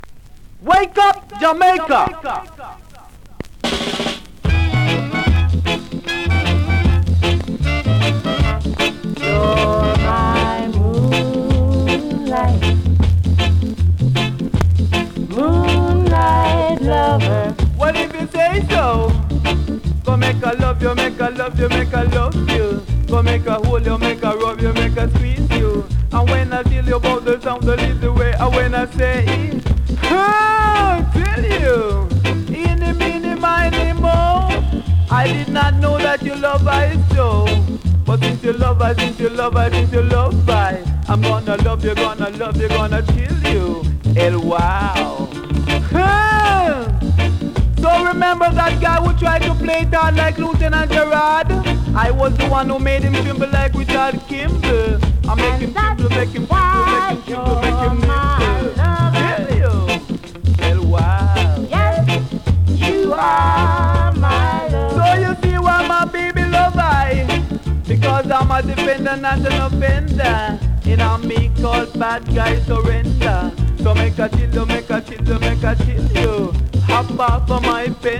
2026!! NEW IN!SKA〜REGGAE
スリキズ、ノイズ比較的少なめで